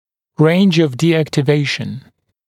[reɪnʤ əv dɪˌæktɪ’veɪʃn][рэйндж ов диˌэкти’вэйшн]диапазон деактивации